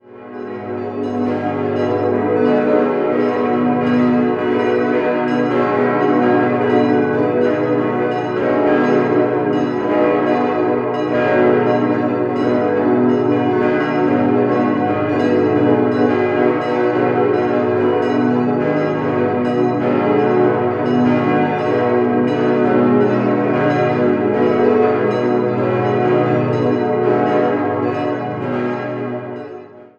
Das Gotteshaus mit dem monumentalen Riegelturm wurde 1954/55 nach den Plänen von Josef Lucas errichtet und im Folgejahr geweiht. 6-stimmiges Geläut: gis°-h°-dis'-fis'-a''-h'' Die vier großen Glocken wurden 2004 bei Bachert in Karlsruhe gegossen, die beiden kleinen 1946 bei Junker in Brilon.